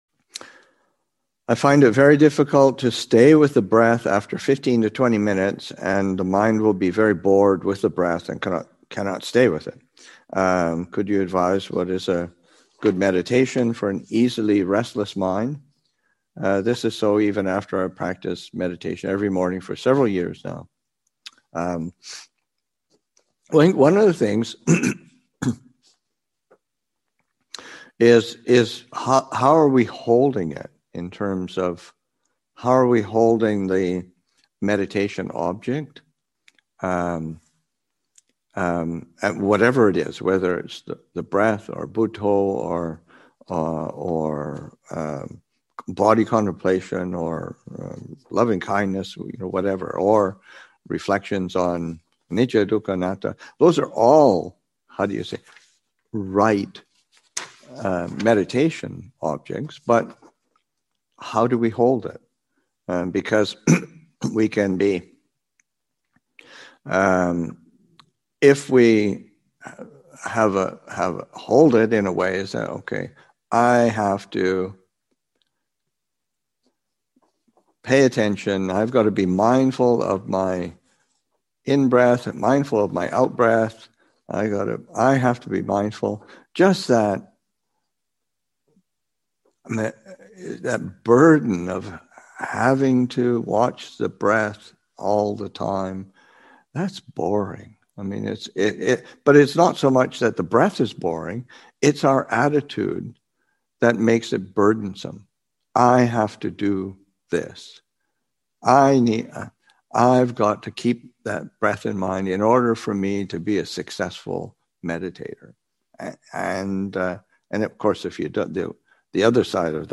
Awaken to the New Year Retreat, Session 1 – Jan. 1, 2021